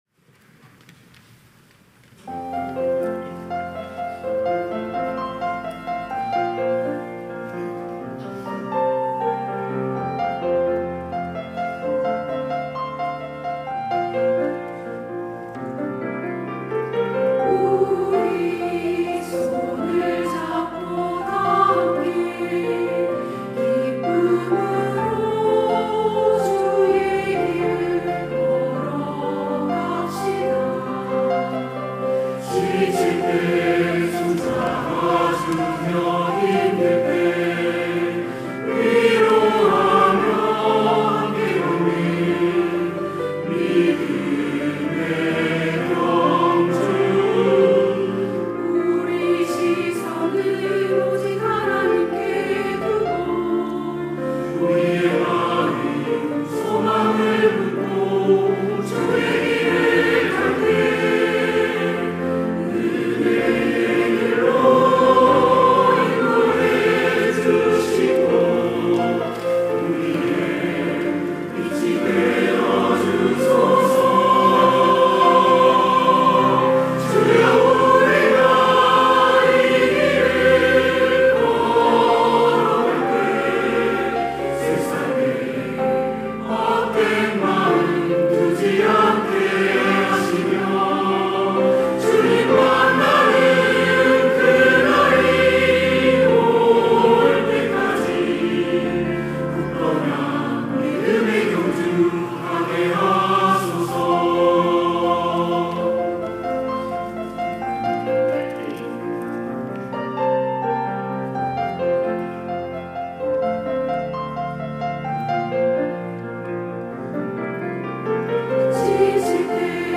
시온(주일1부) - 믿음의 경주
찬양대